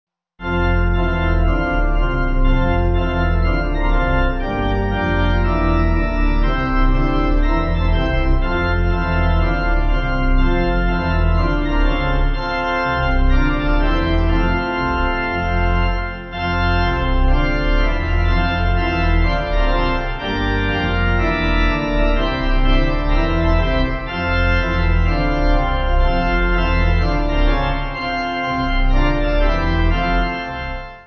sing through twice